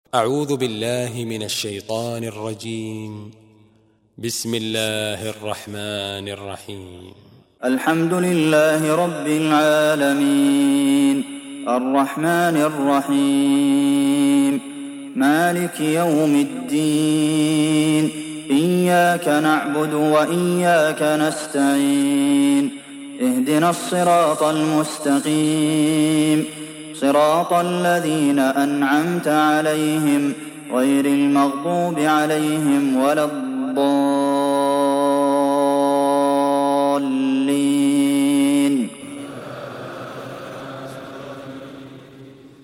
Surat Al Fatiha mp3 Download Abdulmohsen Al Qasim (Riwayat Hafs)